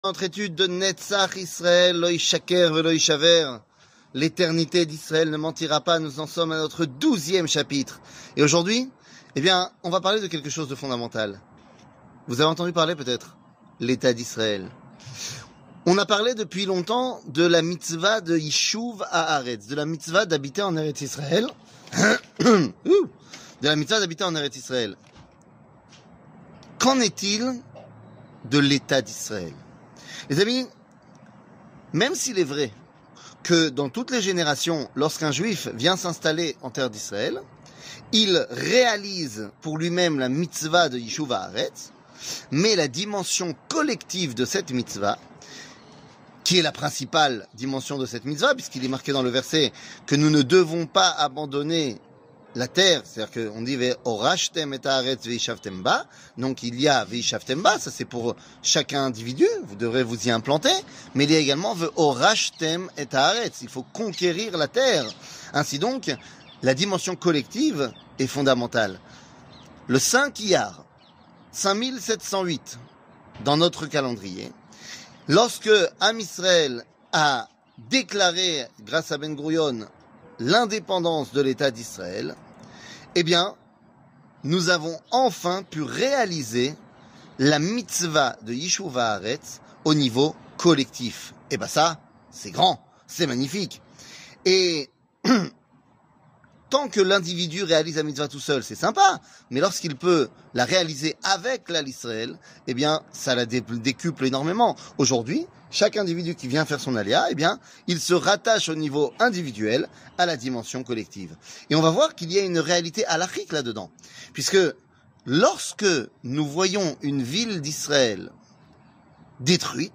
L'éternité d'Israel ne mentira pas ! 12 00:07:59 L'éternité d'Israel ne mentira pas ! 12 שיעור מ 23 אוקטובר 2023 07MIN הורדה בקובץ אודיו MP3 (7.3 Mo) הורדה בקובץ וידאו MP4 (12.75 Mo) TAGS : שיעורים קצרים